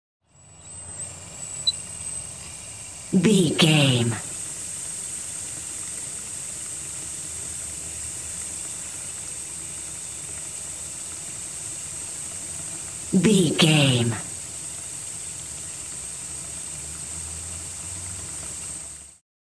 Emergency Oxygen Mask
Sound Effects
chaotic
anxious